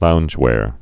(lounjwâr)